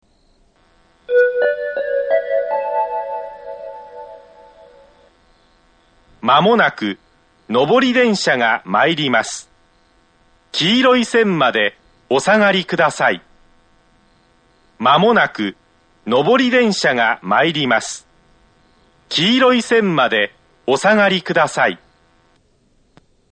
１番線接近放送